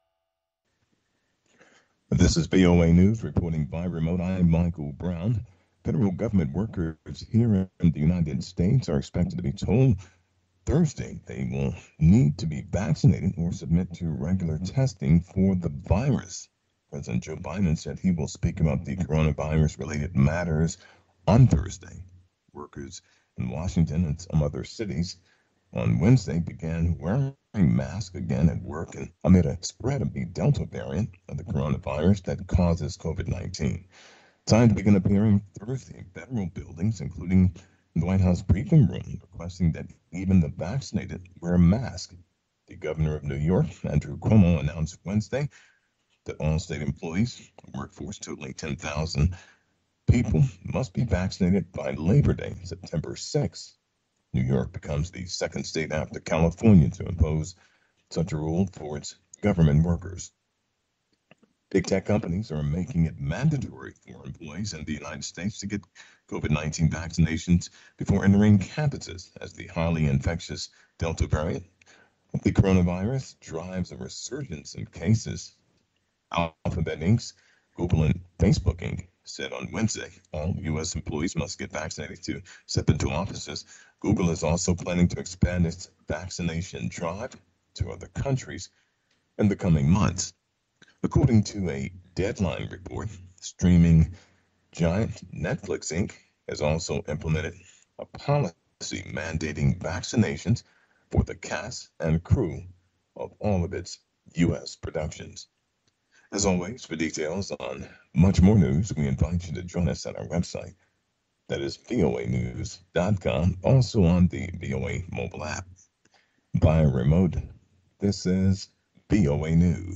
VOA Newscasts